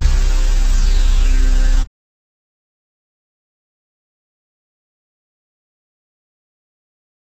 MDMV3 - Hit 16.wav